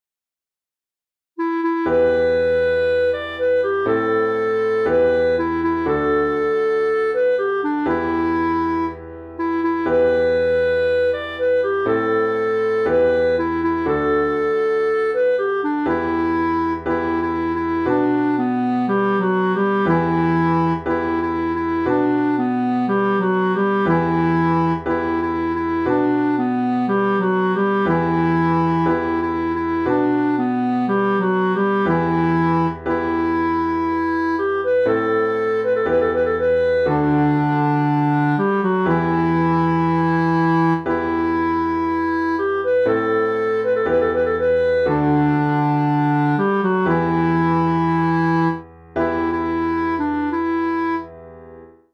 Salam Alaikum (Cançó tradicional del Marroc)